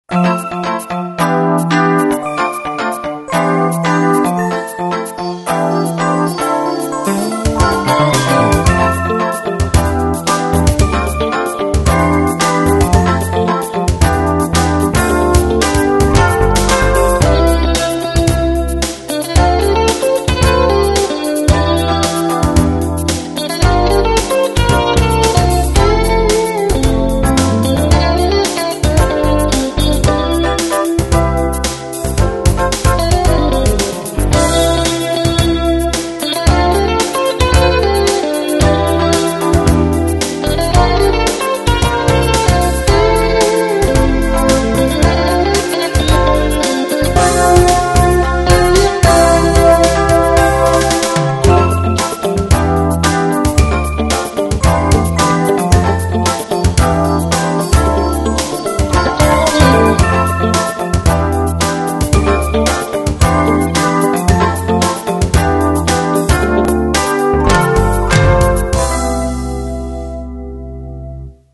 Major
明るくとても軽快